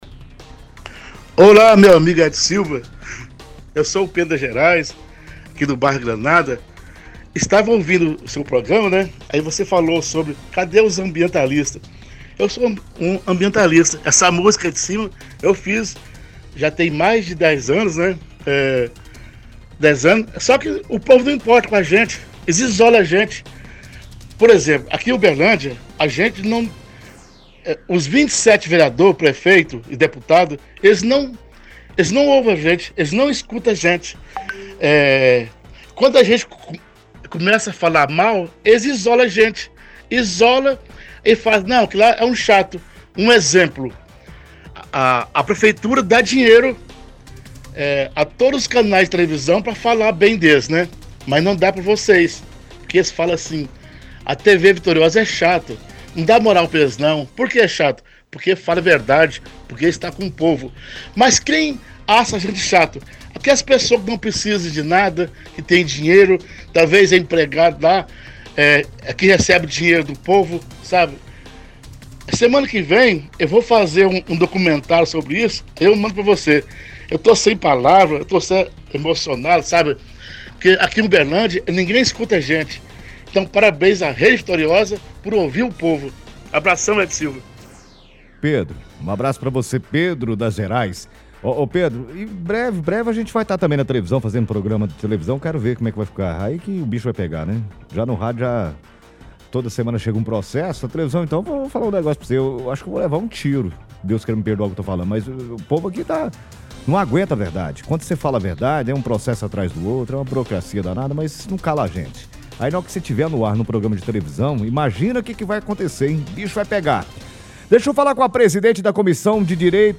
Ligação Ouvintes